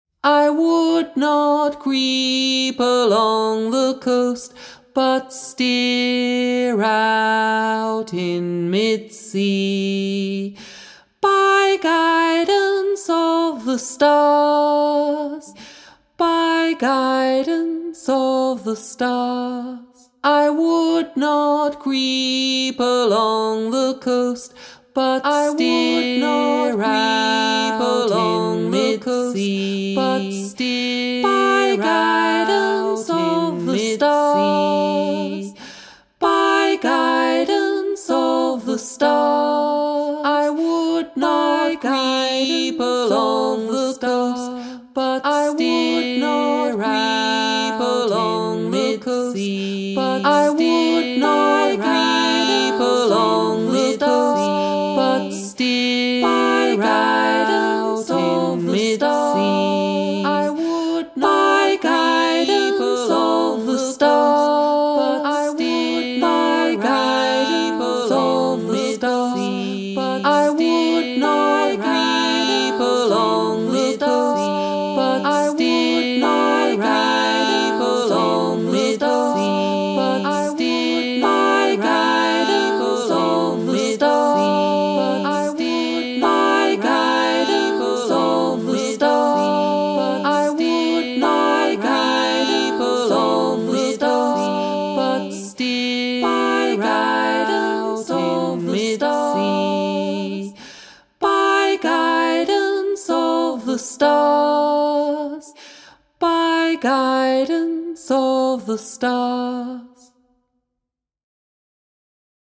Rounds and Canons